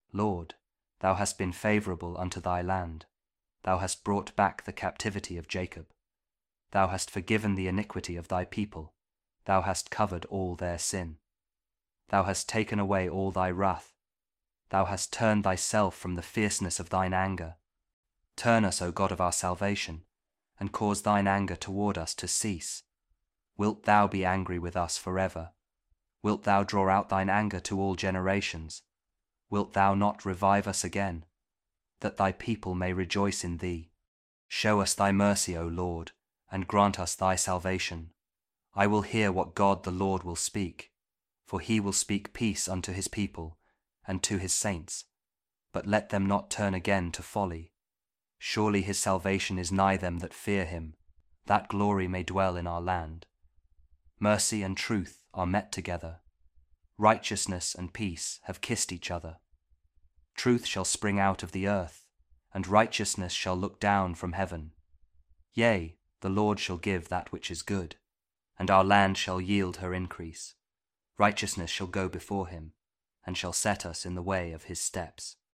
Psalm 85 | King James Audio Bible